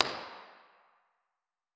MIT_environmental_impulse_responses